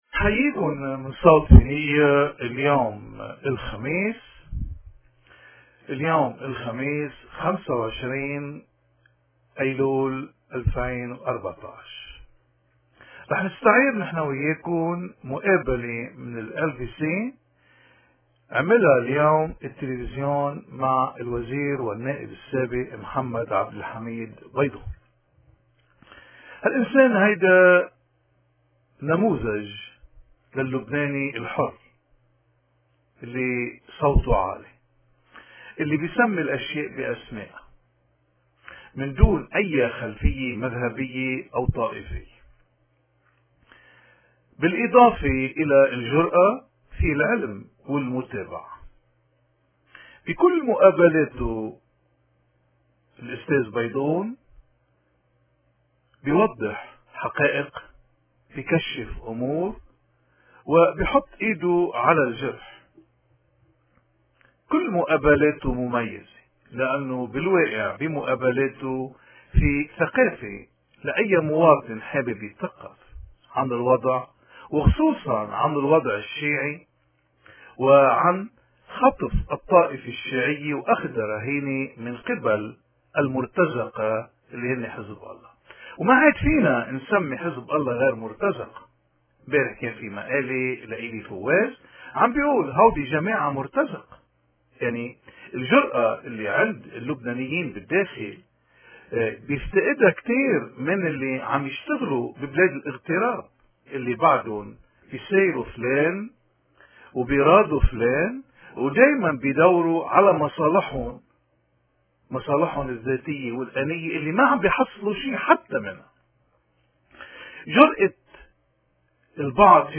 من تلفزيون ال بي سي مقابلة مع الوزير السابق محمد عبد الحميد بيضون